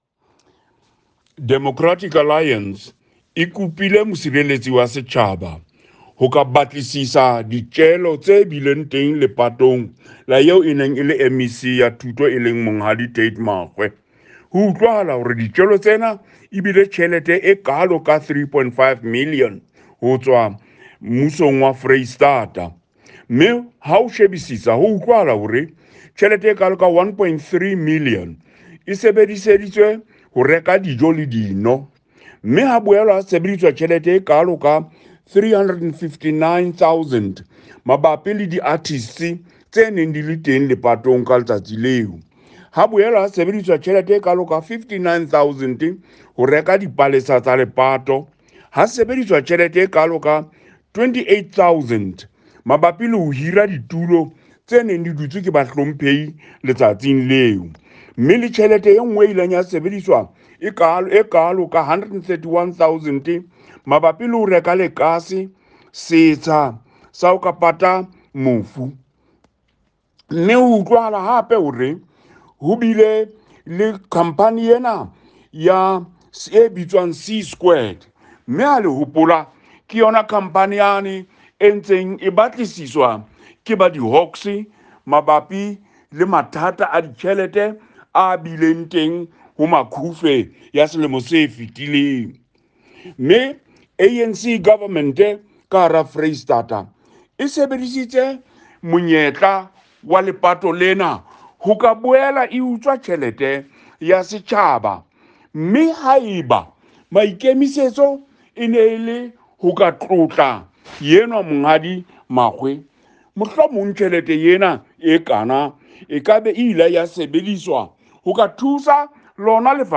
Sesotho soundbite by James Letuka MPL